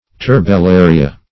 Turbellaria \Tur`bel*la"ri*a\, n. pl. [NL., dim. fr. L. turbo a